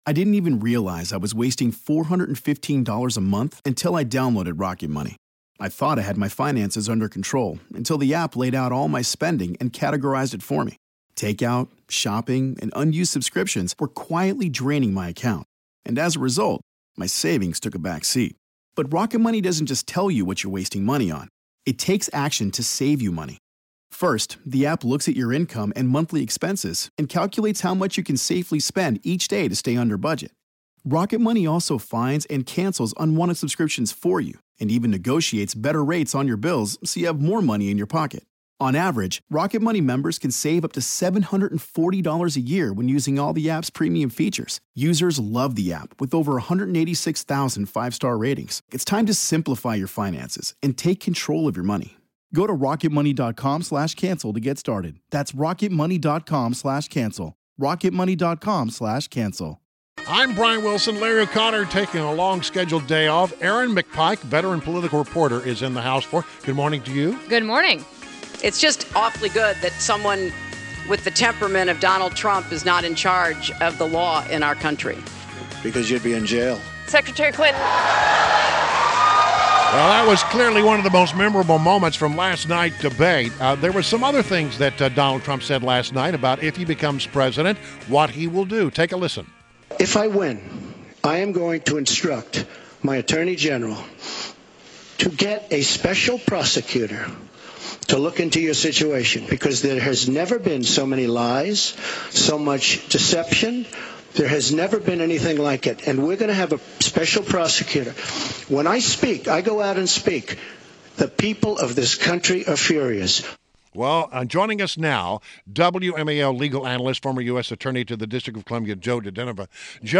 INTERVIEW — JOE DIGENOVA – WMAL Legal analyst and former U.S. Attorney to the District of Columbia